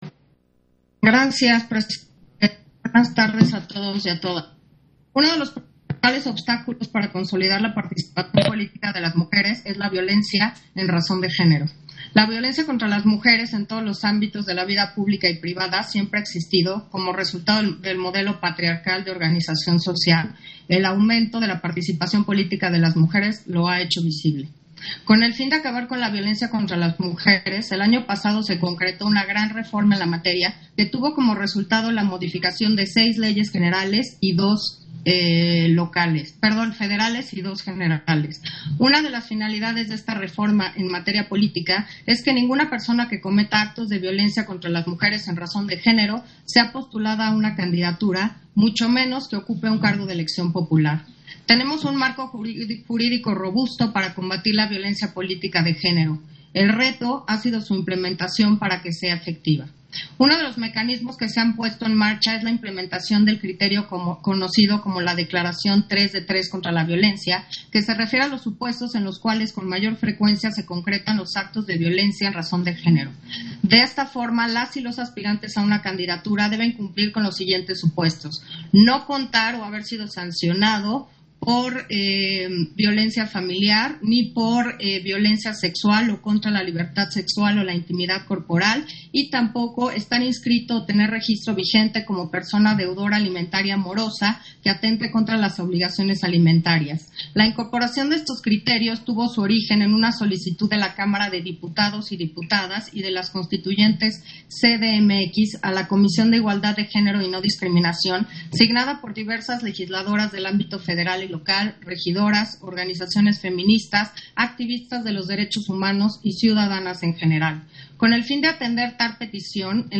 030421_AUDIO_INTERVENCIÓN-CONSEJERA-CARLA-HUMPHREY-PUNTO-2-SESIÓN-EXT. - Central Electoral